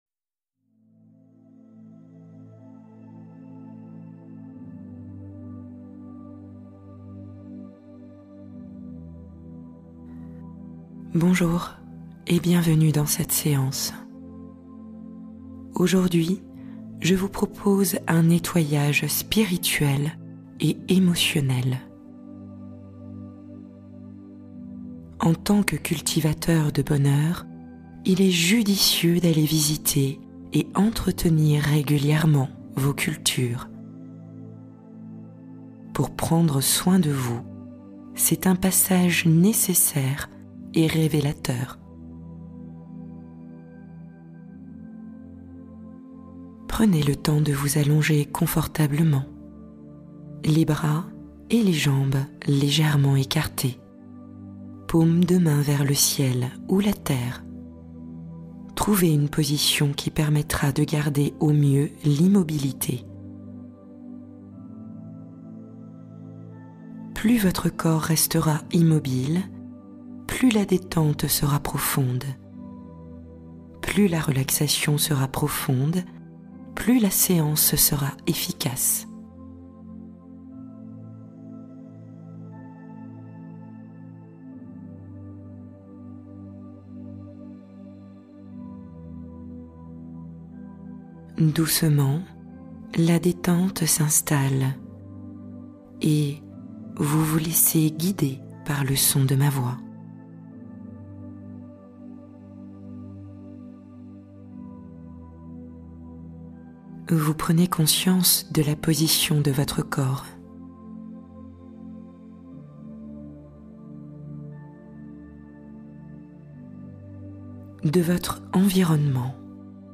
Ouverture du cœur : détente guidée pour rééquilibrer l’énergie intérieure